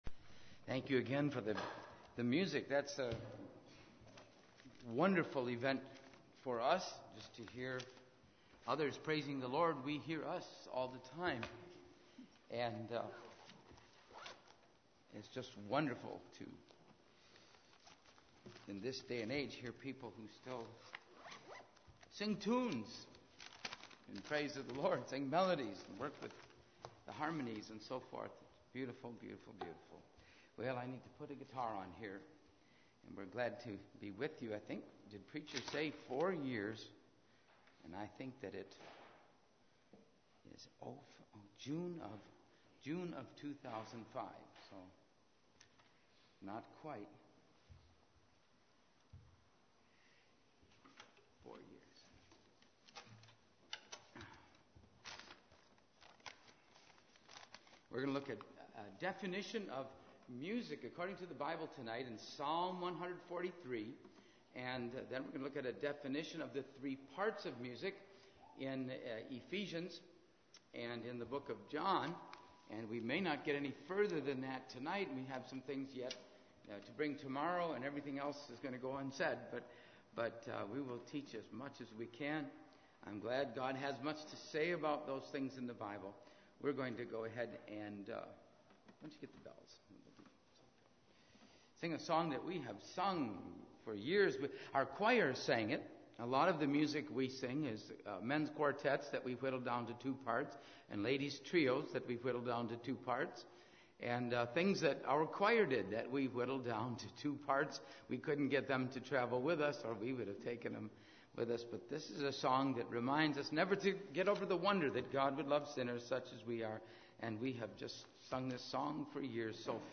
In this sermon, the preacher emphasizes the importance of music in praising the Lord.